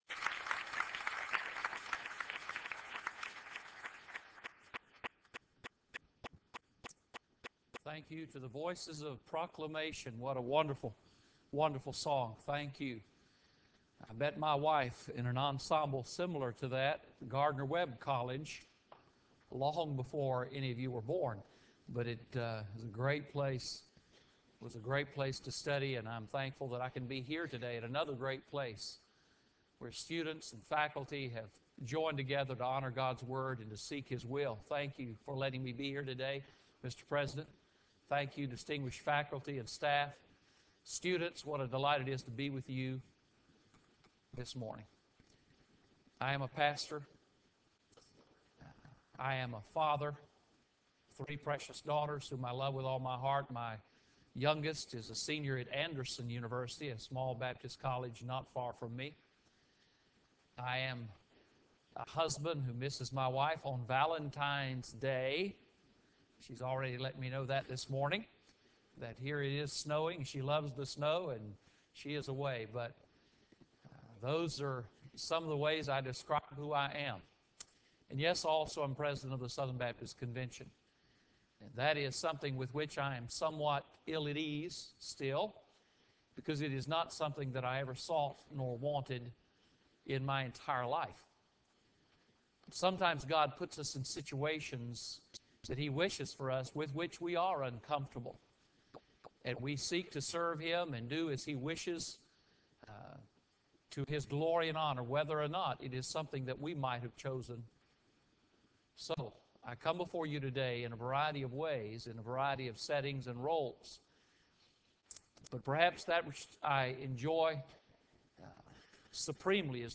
Baptist Identity II Chapel: Frank Page